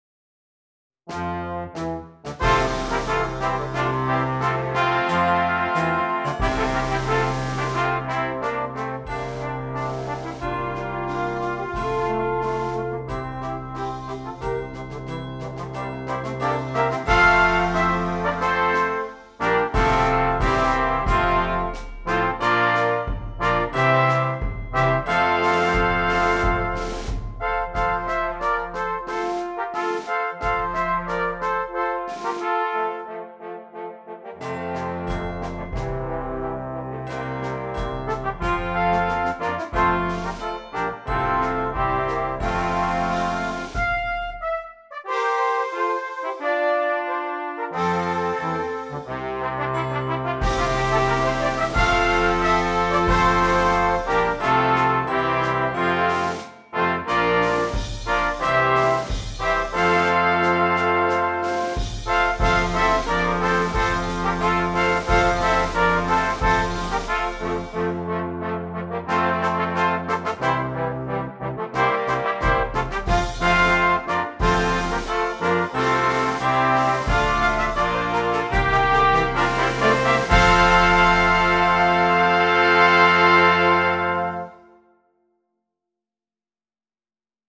Brass Choir
Traditional
Christmas carol